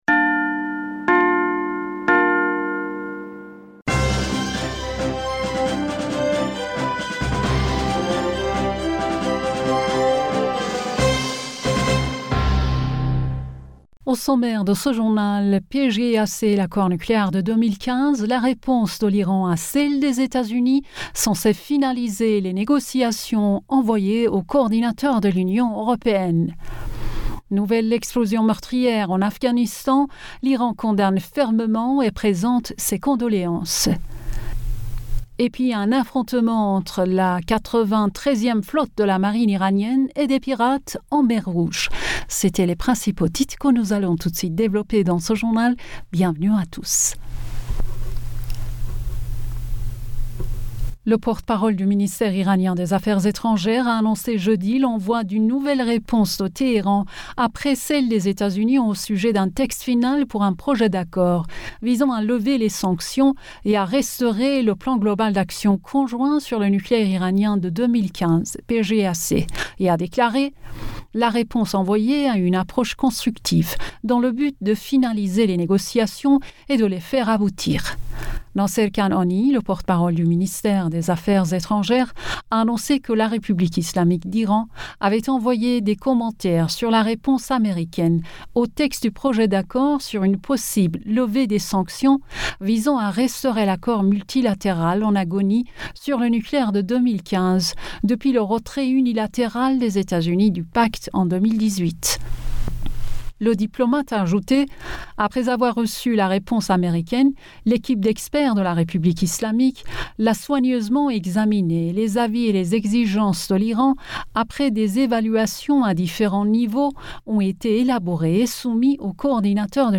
Bulletin d'information Du 02 Septembre